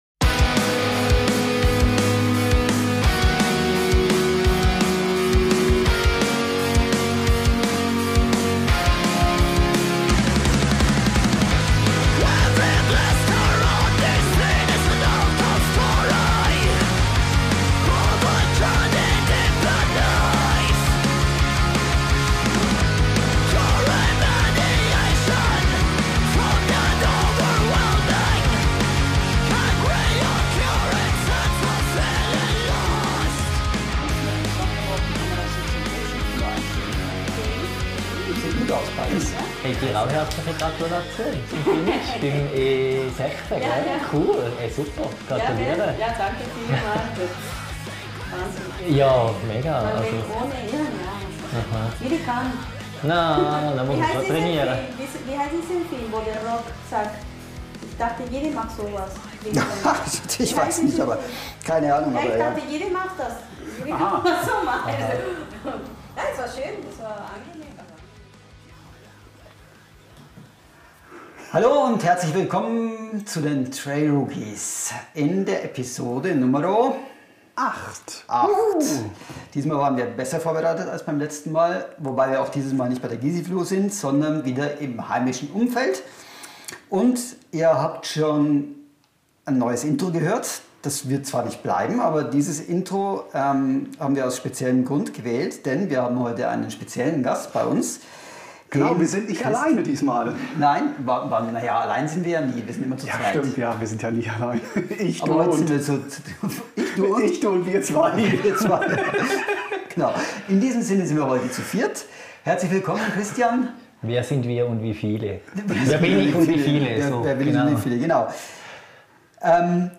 In dieser Interviewfolge